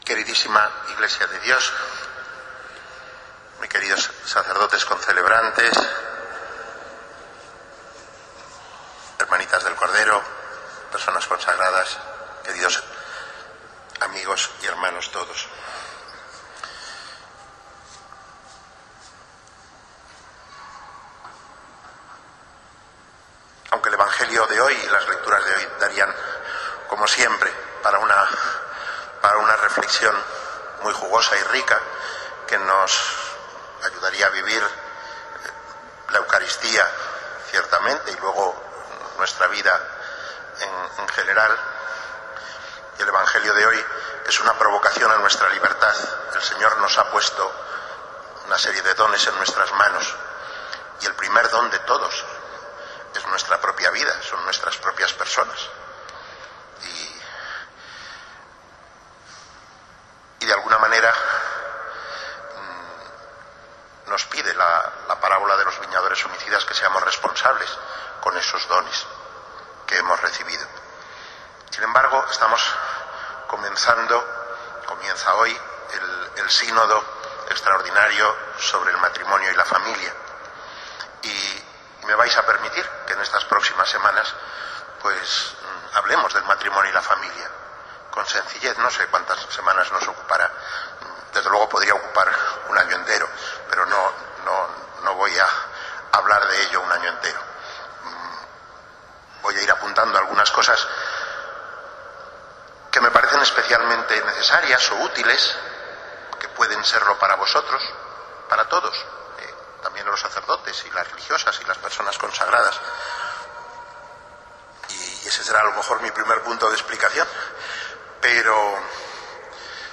Homilía en la Eucaristía en la S.I Catedral en el XXVII Domingo del Tiempo Ordinario, el mismo día que comenzó el Sínodo Extraordinario de obispos sobre el matrimonio y la familia. El Arzobispo habla sobre el matrimonio como relación única, donada libremente, y aborda algunos aspectos sobre el matrimonio, el amor esponsal y la familia para conocer su naturaleza.
5_oct_2014._Eucarista_S.I_Catedral.mp3